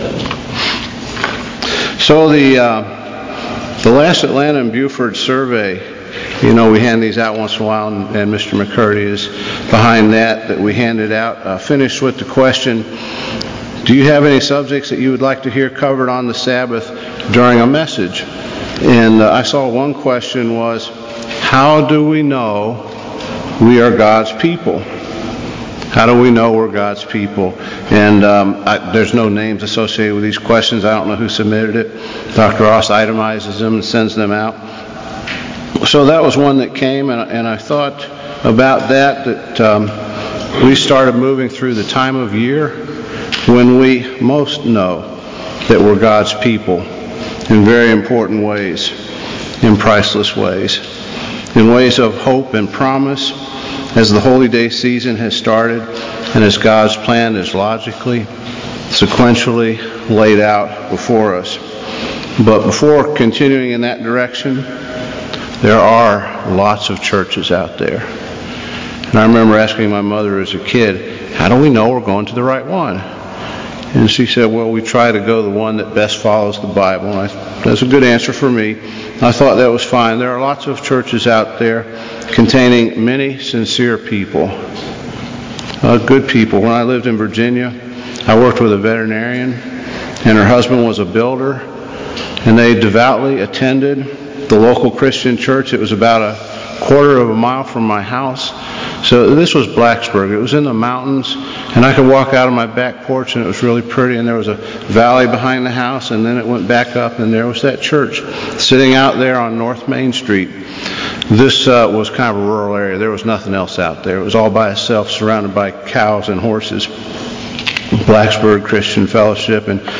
Given in Buford, GA
In this split-sermon